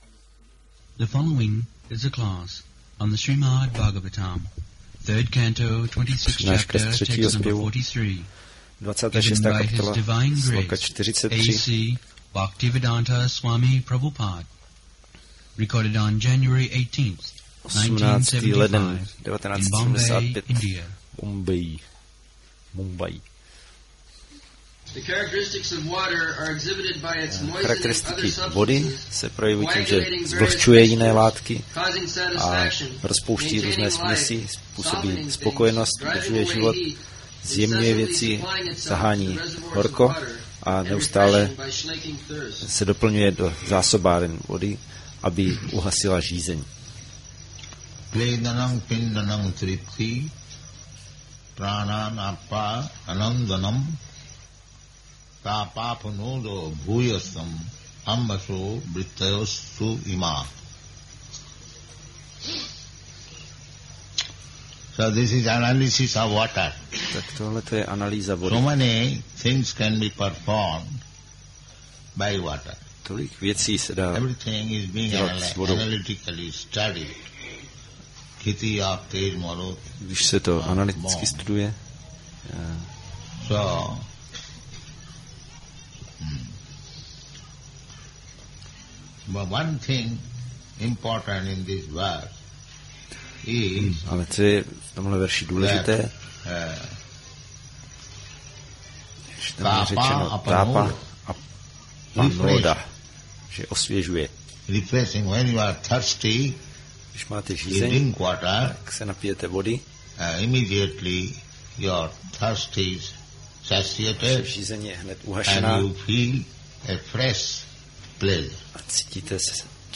1975-01-18-ACPP Šríla Prabhupáda – Přednáška SB-3.26.43 Refreshment for Parched Mind